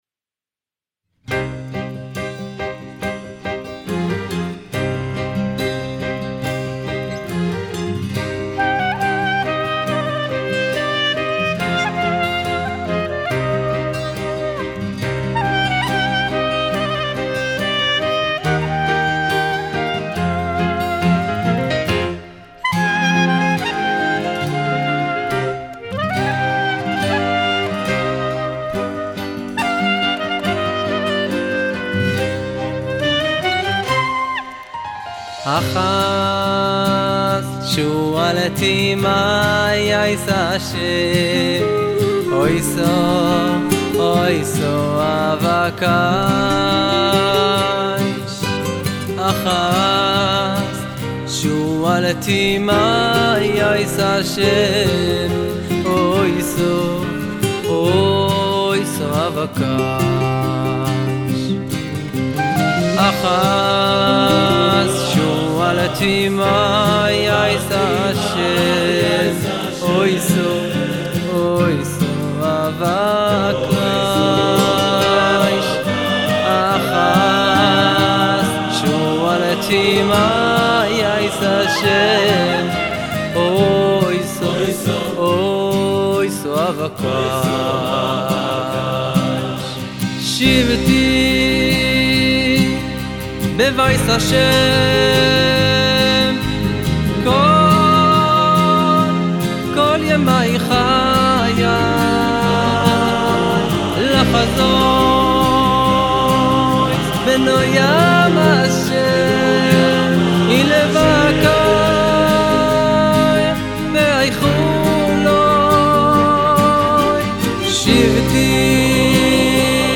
בלווי קולי